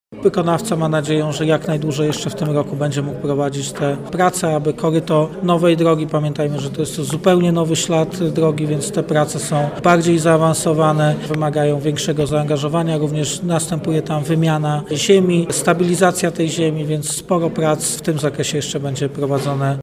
Prezydent Lucjusz Nadbereżny przyznał, że nie jest to łatwa inwestycja